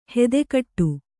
♪ hede kaṭṭu